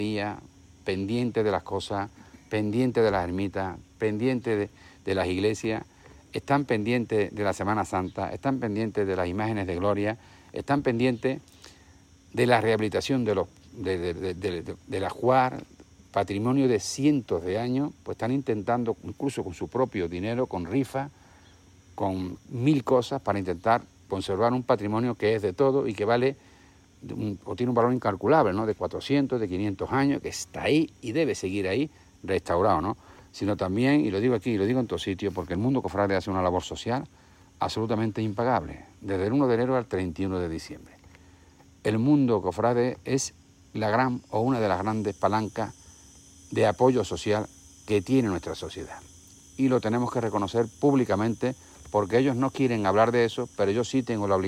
SONIDO.-Visita-Cabra.-Salvador-Fuentes-3.mp3